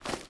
STEPS Dirt, Run 21.wav